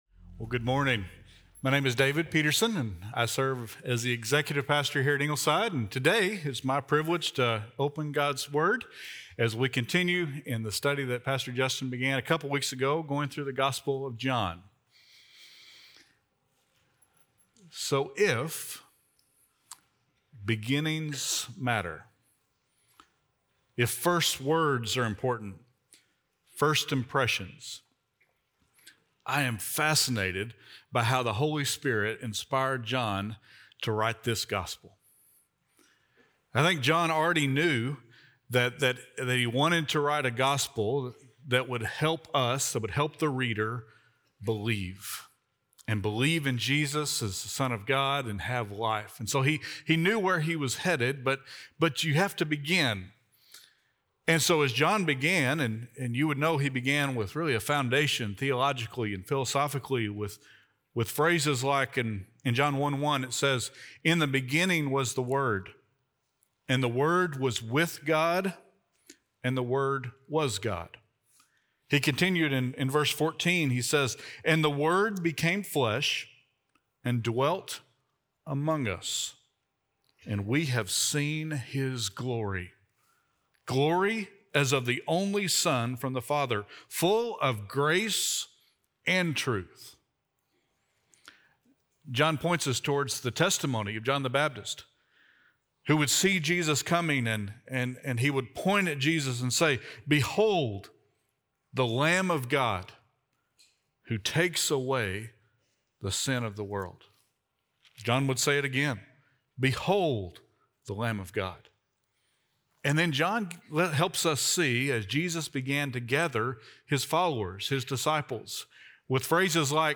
Miracles with a Meaning - Sermon - Ingleside Baptist Church